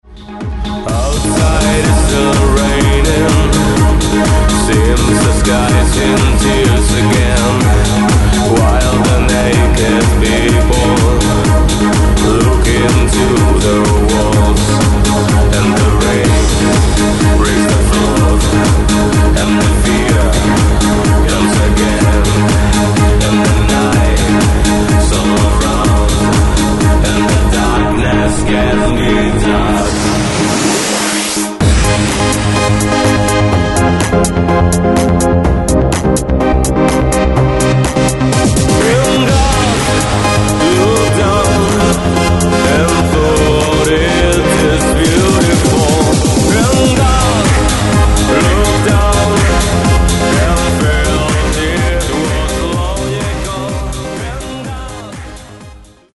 Synth Pop